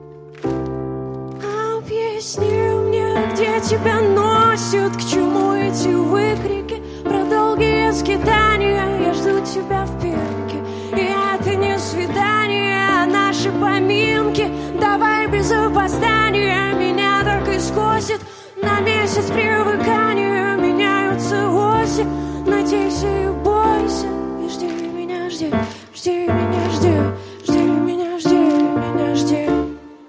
• Качество: 131, Stereo
женский вокал
вживую
авторская песня